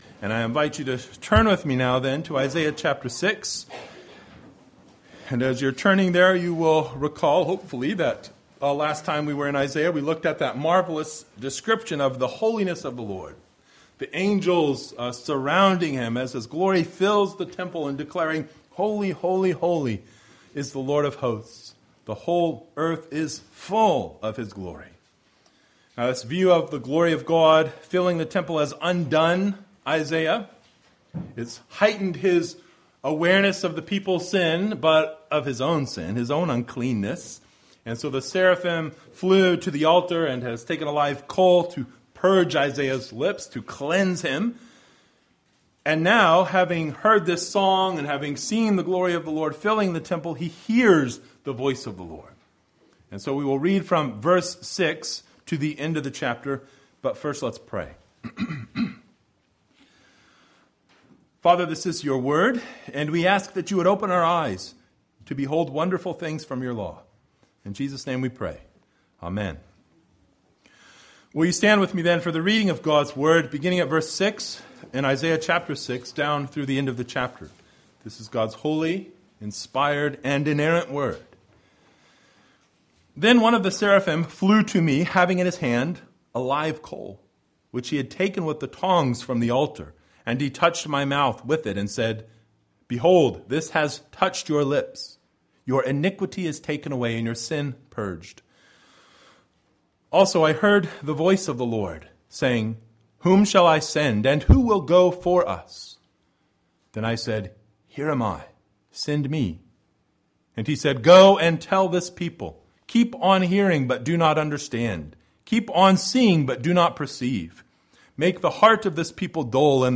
Isaiah 6:6-13 Service Type: Sunday Evening Bible Text